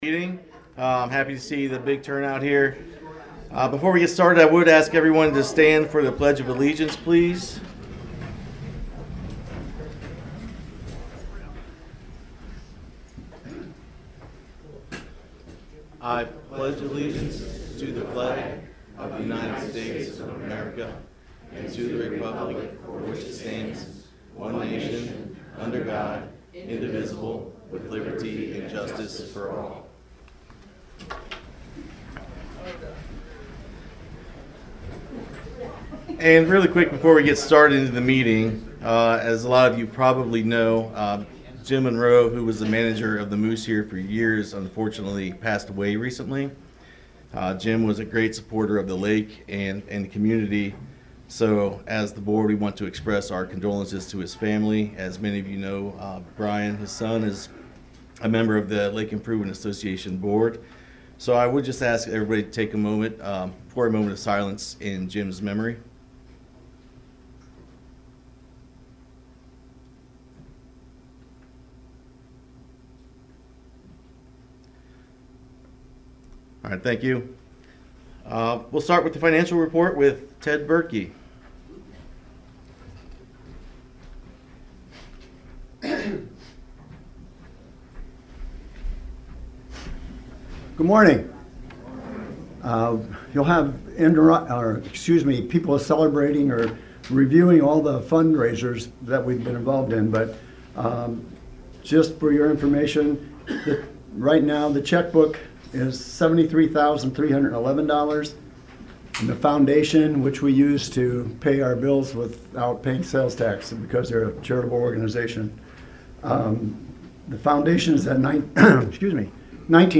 Meeting Notes
The Lake Improvement Association held its Monthy meeting Saturday Morning March 1st.